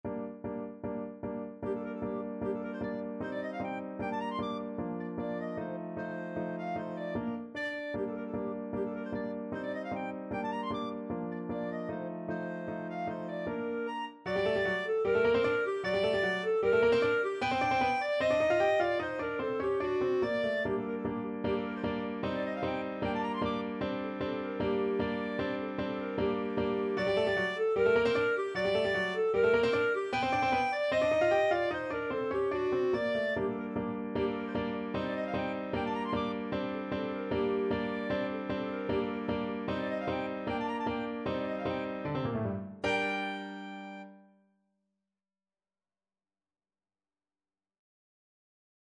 Clarinet
G minor (Sounding Pitch) A minor (Clarinet in Bb) (View more G minor Music for Clarinet )
2/4 (View more 2/4 Music)
Allegro scherzando (=152) (View more music marked Allegro)
Classical (View more Classical Clarinet Music)